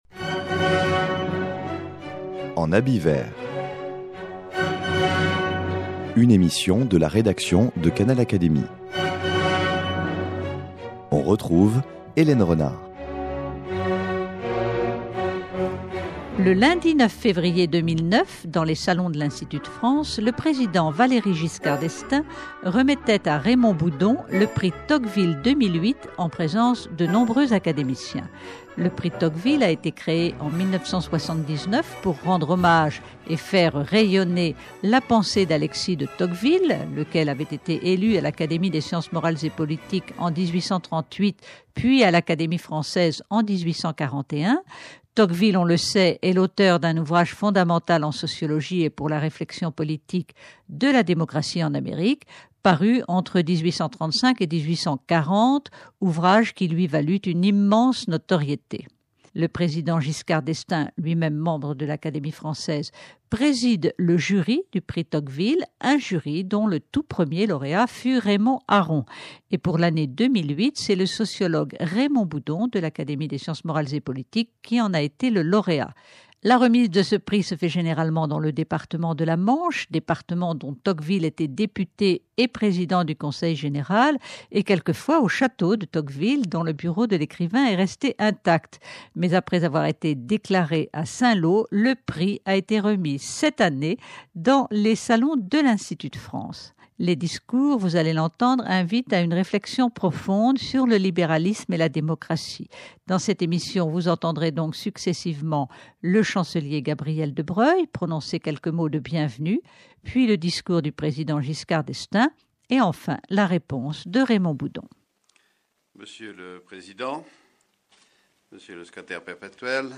Le président Valéry Giscard d’Estaing a remis à Raymond Boudon le prix Tocqueville 2008, le lundi 9 février 2009, dans les salons de l’Institut de France, en présence de nombreux académiciens. Dans cette émission, vous entendrez successivement le Chancelier Gabriel de Broglie, le discours du Président Giscard d’Estaing, de l’Académie française, président du jury du prix Tocqueville et la réponse du sociologue Raymond Boudon, de l’Académie des sciences morales et politiques.